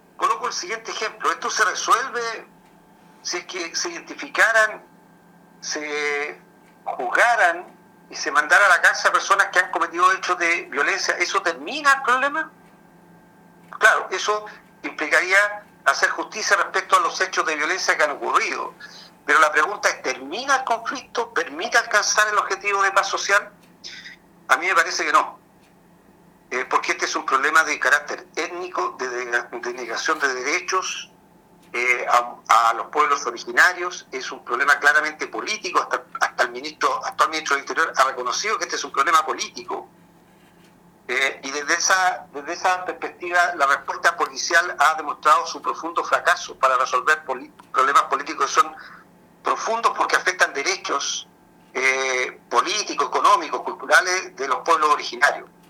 En conversación con Nuestra Pauta, el parlamentario comenzó señalando que el interés general es recuperar la paz social y garantizar el derecho a vivir en un ambiente tranquilo y con seguridad.